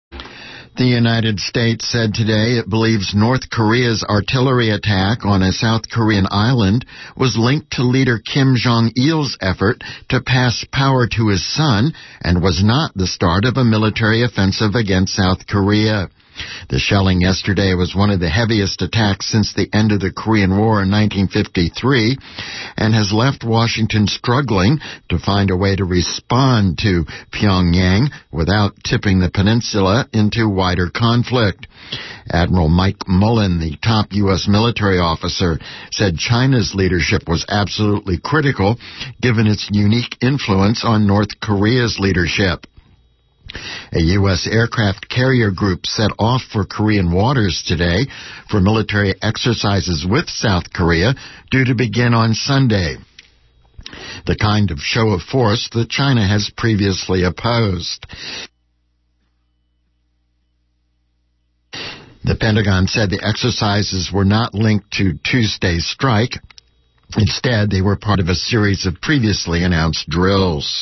Here are the excerpts regarding Korea from the KPFA news headlines at Noon and 4 PM today.